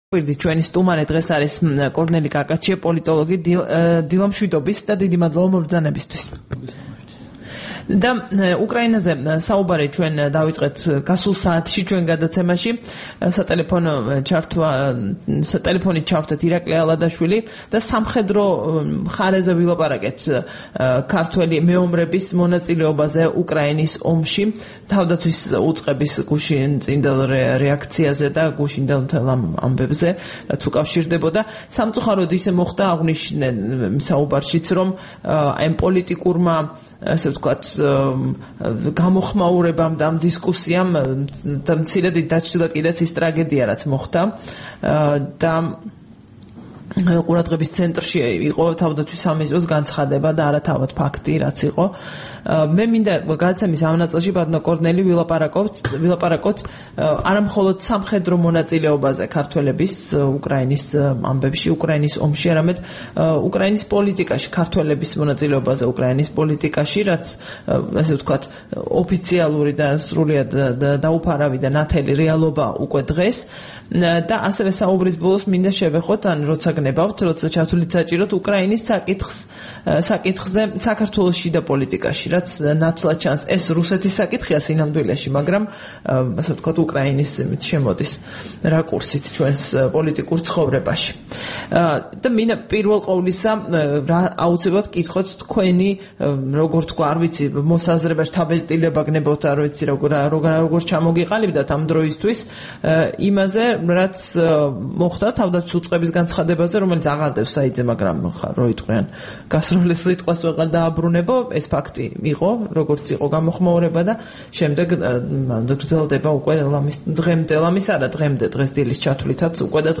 სტუმრად ჩვენს ეთერში
საუბარი